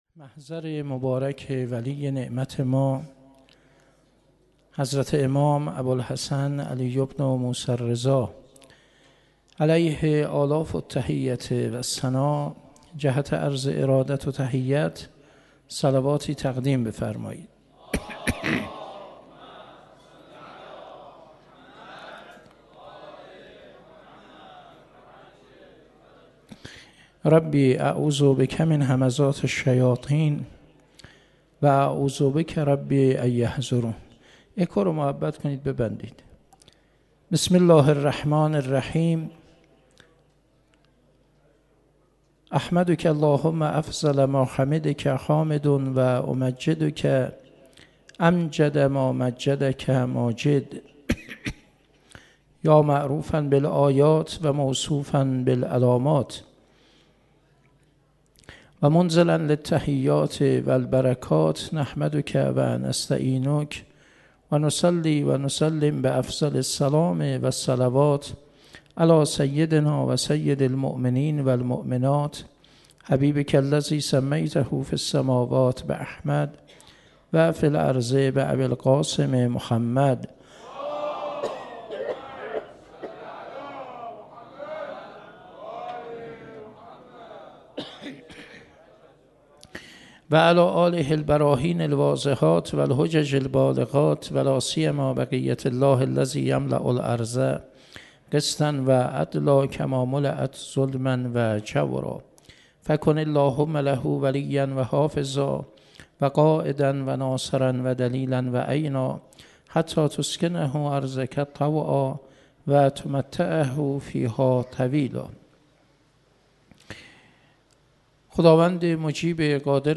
30 اردیبهشت 97 - حسینیه انصار الحسین - سخنرانی